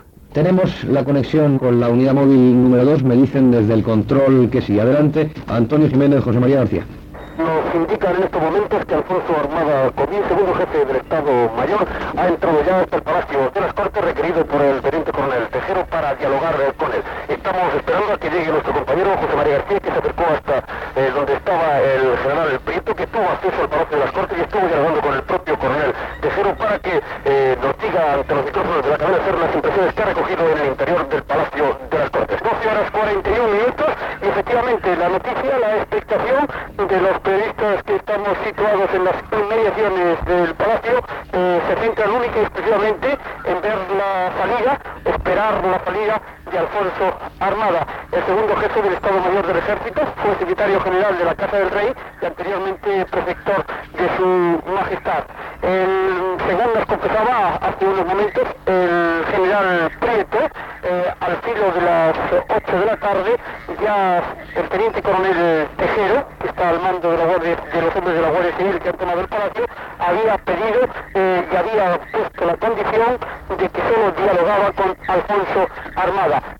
Connexió, a la matinada, amb la unitat mòbil de la SER, a l'exterior del Congrés de Diputats de Madrid. Des de la tarda anterior, un grup de guàrdia civils tenen segrestats als diputats en l'intent de fer un cop d'estat.
Informatiu